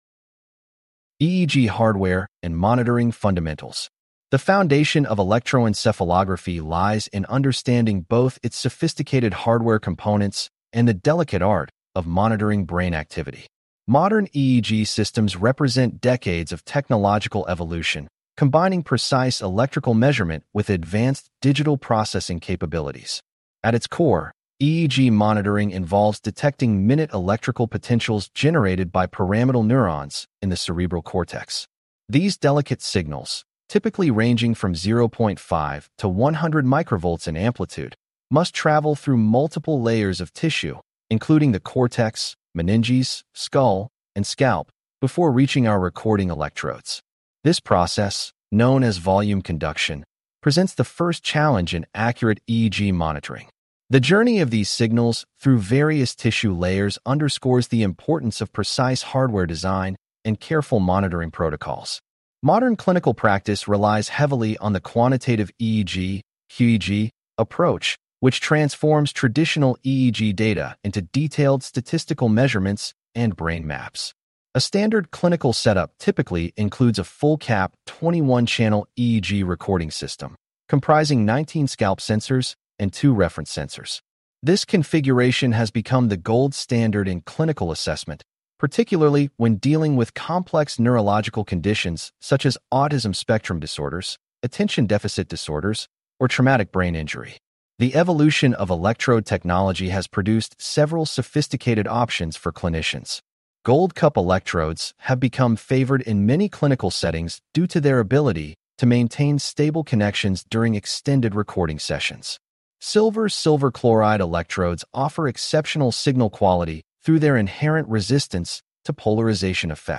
This unit covers Electrodes, Electroencephalograph, EEG Filters Define the EEG Signal, International 10-20 System, Modified Combinatorial Nomenclature, 10-20 System Electrode Placement, Site Preparation, Elimination of Artifact from EEG Recording, Recognizing Normal EEG Patterns, EEG Displays, and Drug Effects on the EEG. Please click on the podcast icon below to hear a full-length lecture.